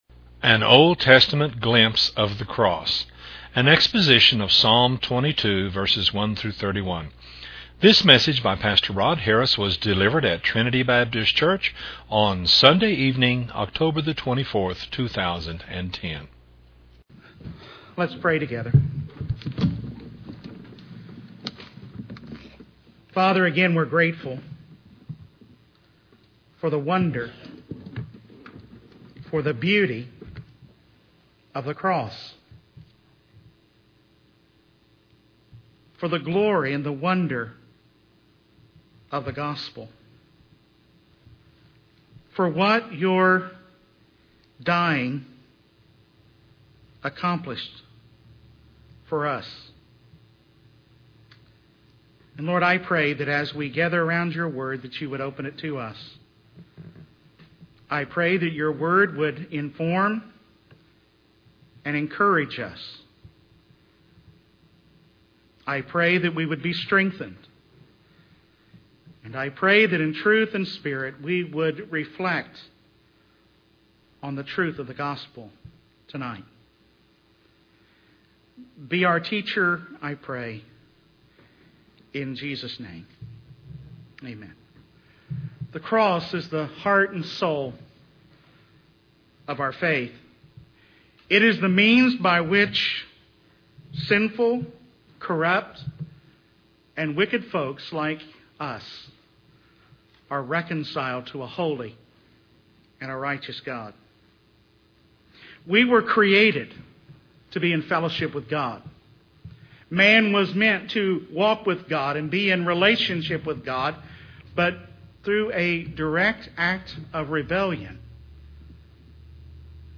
on the occasion of the observance of the Lord's Table on Sunday evening, October 24, 2010.